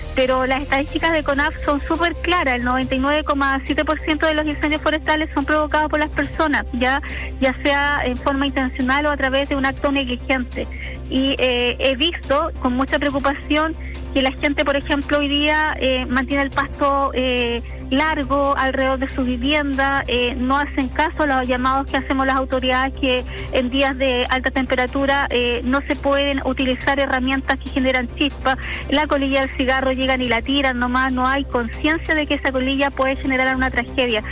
A propósito del incendio forestal ocurrido en la localidad de Pucatrihue, La Radio conversó con la delegada presidencial provincial de Osorno, Claudia Pailalef, quien comentó el análisis de la emergencia.